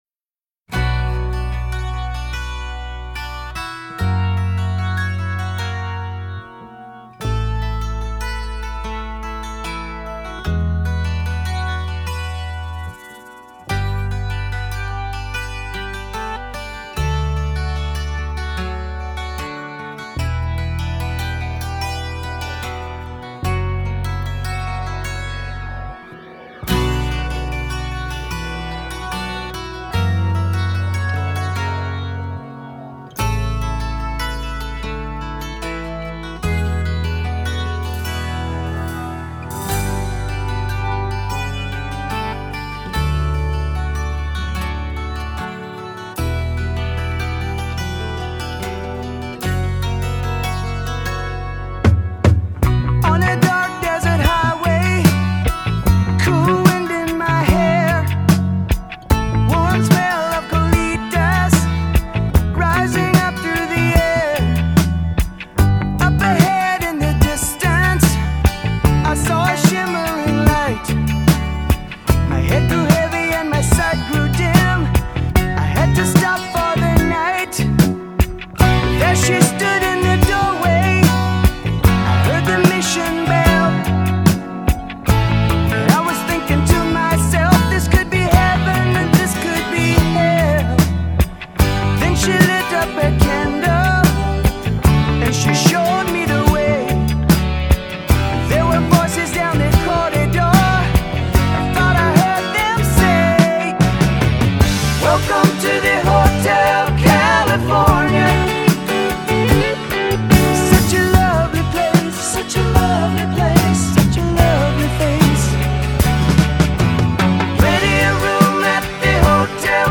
rock songs
electric guitar